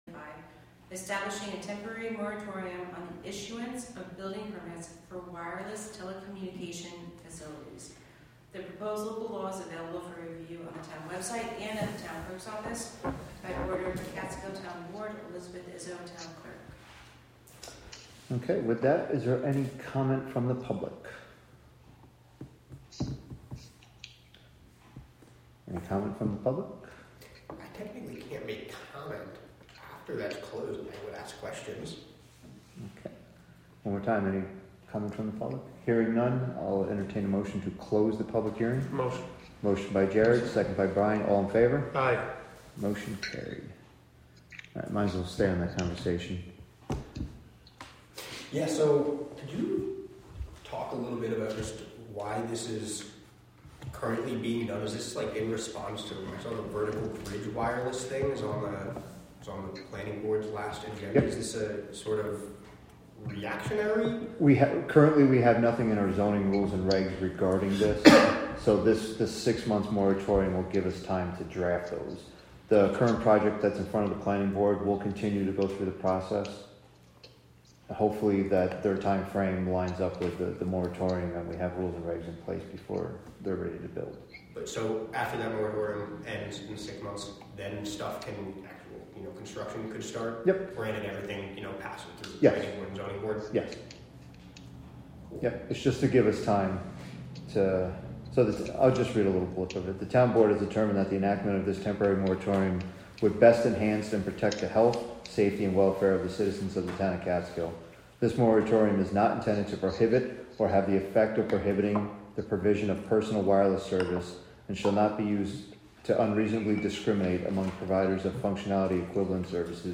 Live from the Town of Catskill: March 19, 2025 Catskill Town Board Meeting (Audio)